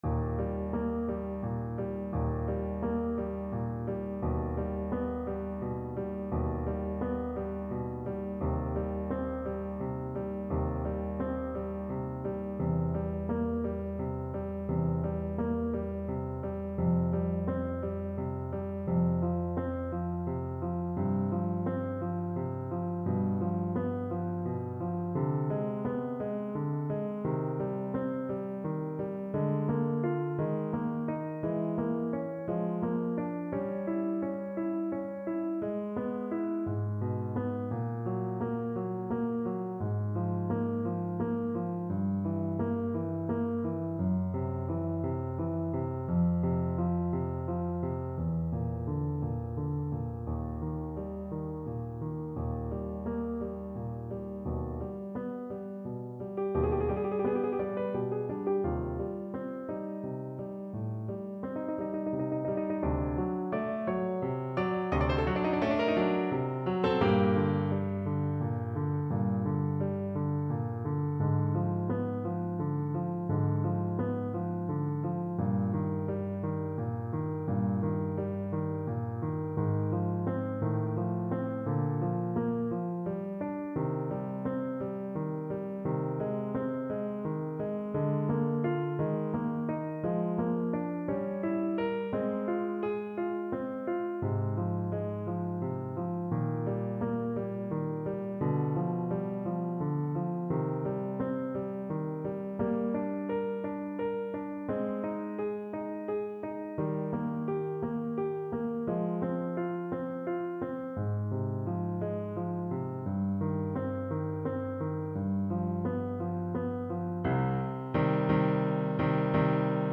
Classical (View more Classical Clarinet Music)